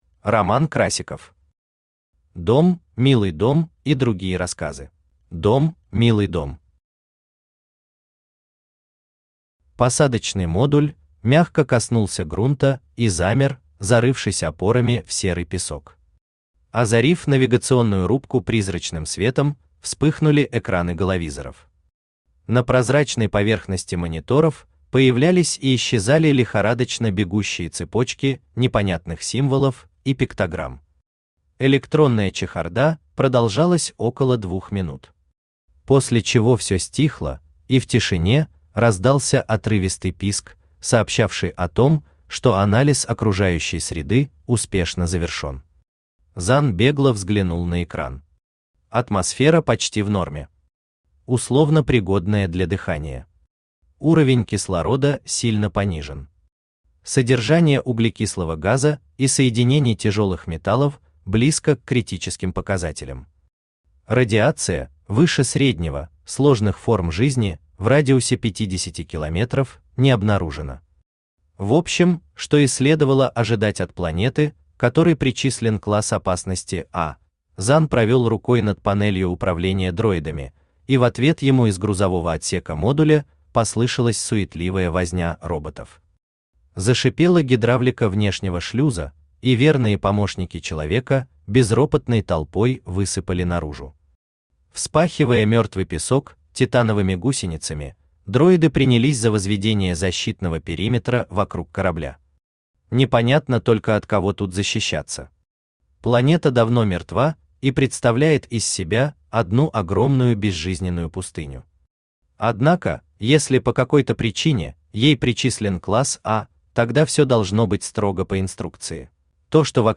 Аудиокнига Дом, милый дом и другие рассказы | Библиотека аудиокниг
Aудиокнига Дом, милый дом и другие рассказы Автор Роман Борисович Красиков Читает аудиокнигу Авточтец ЛитРес.